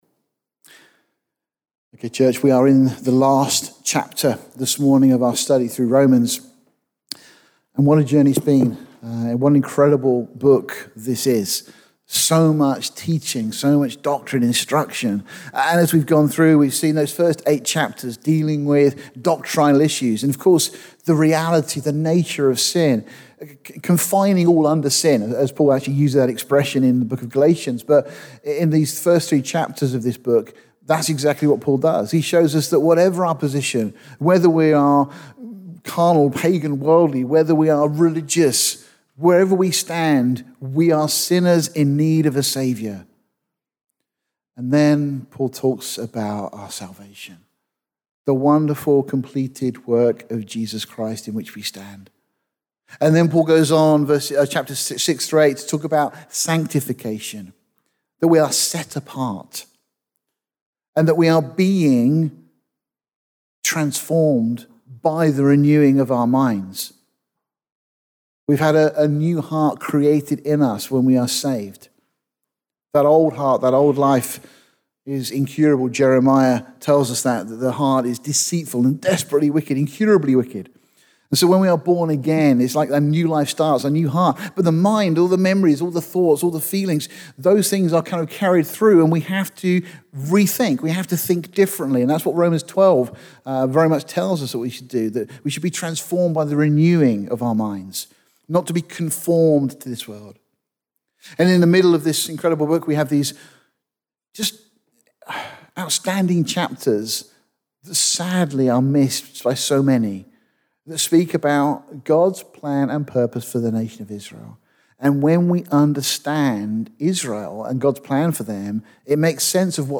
The sermon describes people, their characteristics, responsibilities, duties, generosities, … Their rôle in the spread of the Gospel, by the expansion of the Church, is also expanded upon.